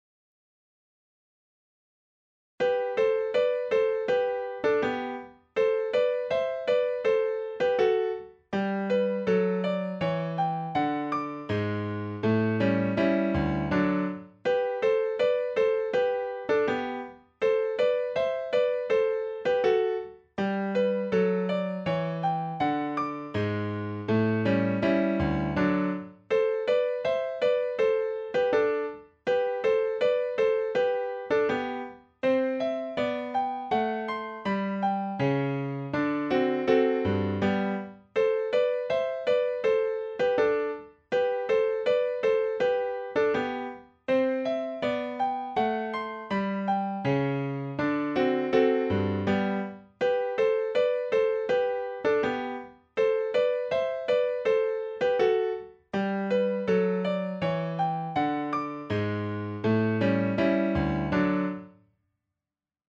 Kafka page 224 - Andante pour pianoforte en 2/4
Encore un Andante en 2/4 pour piano.
Kafka_Pagina224_AndantePerPianoforte.mp3